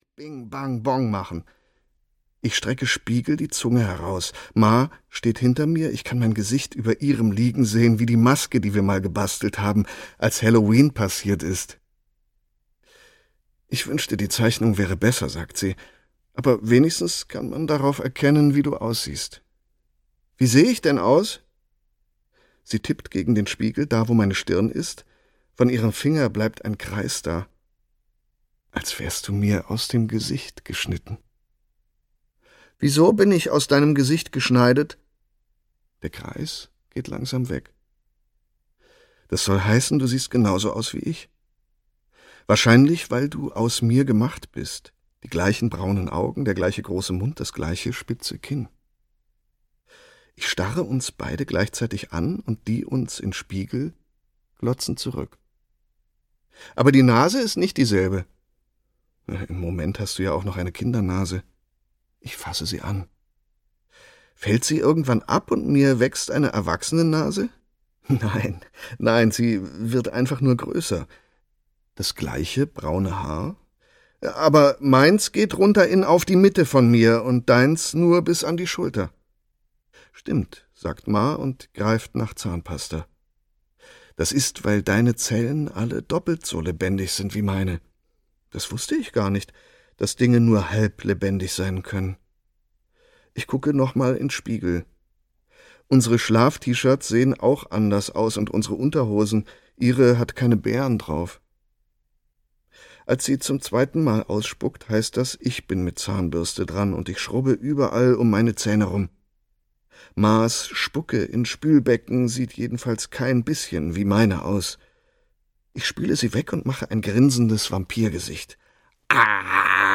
Hörbuch Raum, Emma Donoghue.